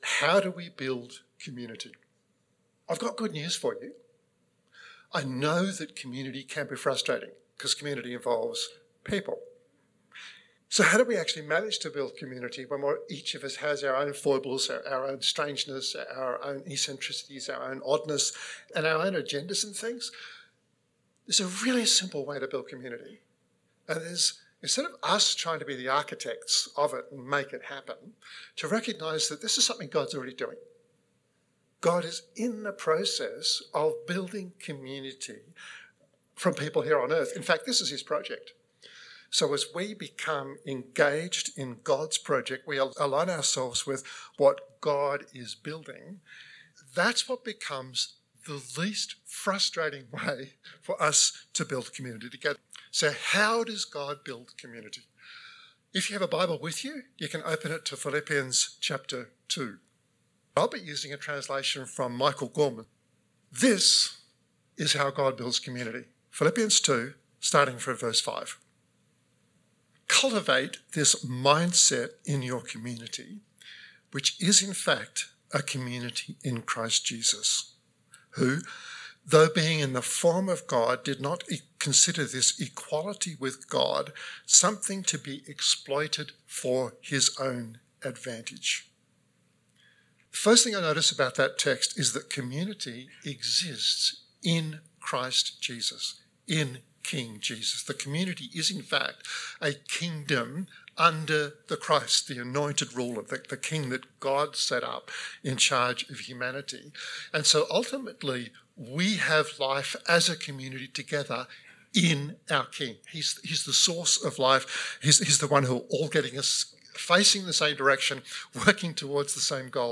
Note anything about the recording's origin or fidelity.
This 21-minute podcast was recorded at Riverview Joondalup 2002-02-13.